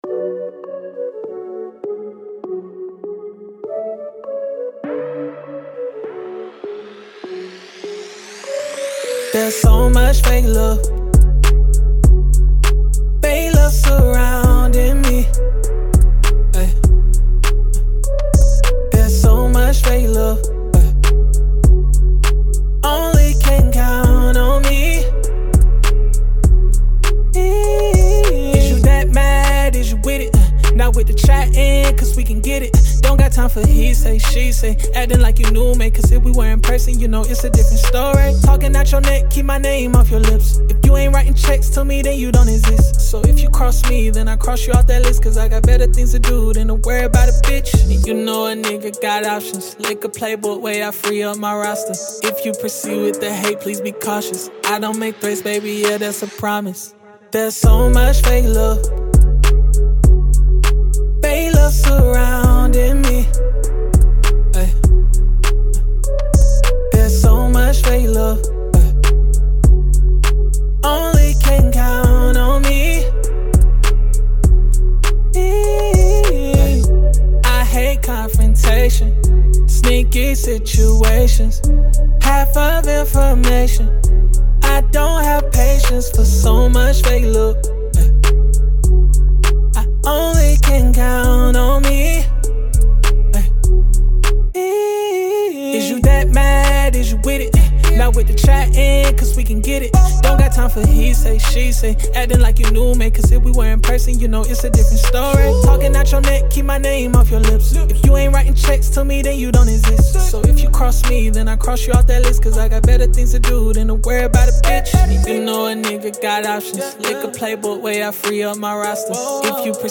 Hip Hop, R&B
F# Minor